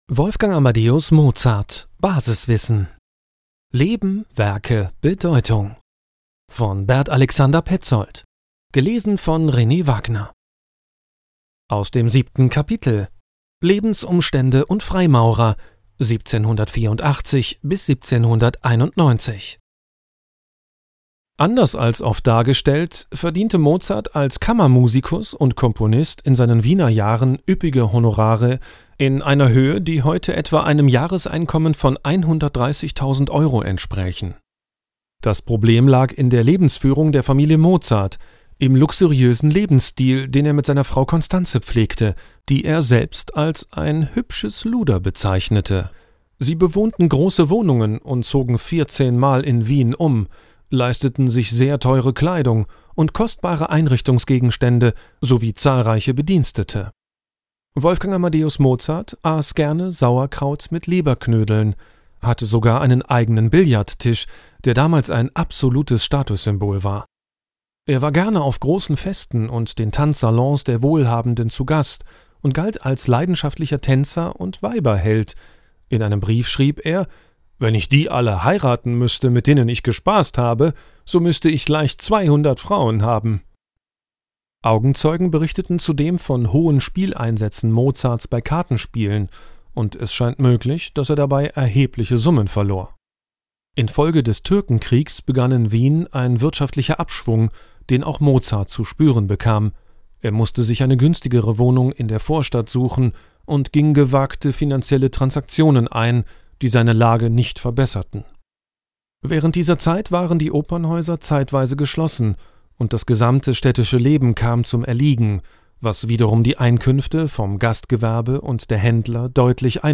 Hörbuch: Wolfgang Amadeus Mozart (2 CDs) – Basiswissen